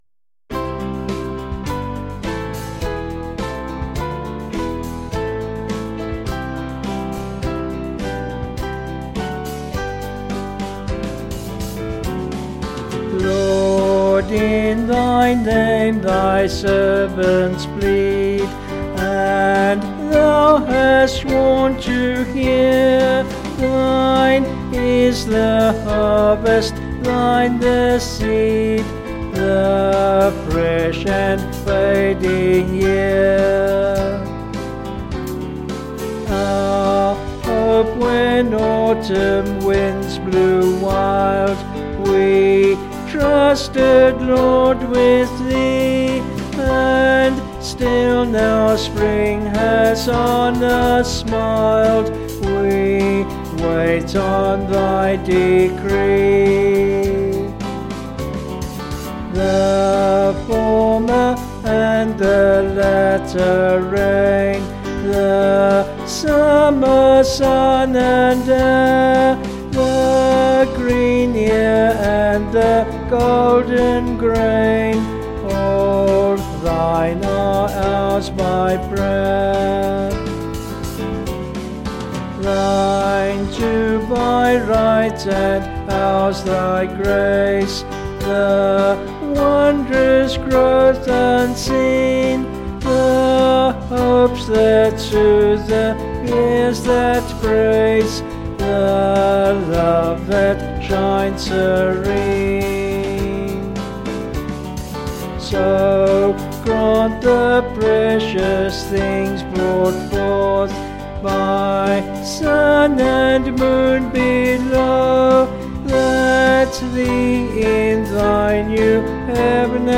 Vocals and Band   264.7kb Sung Lyrics